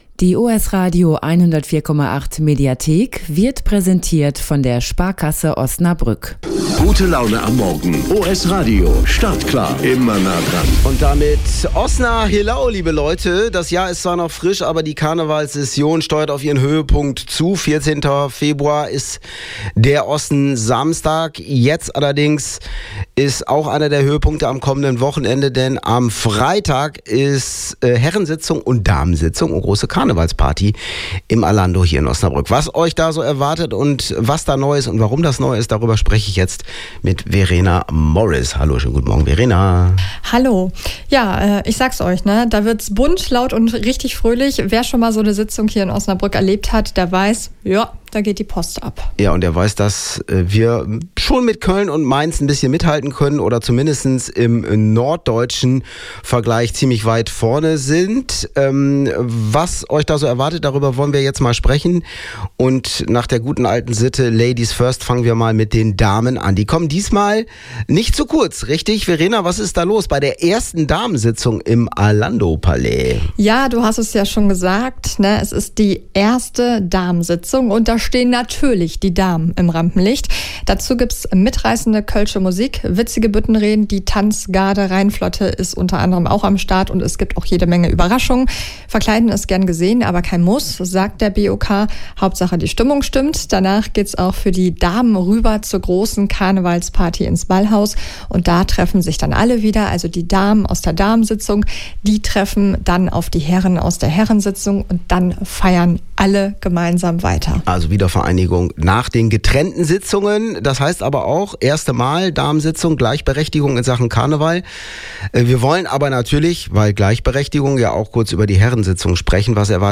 Jetzt reinhören und in Karnevalsstimmung kommen! 2026-01-20 OS-Radio 104,8 Vorbericht Damen und Herrensitzung BOK Download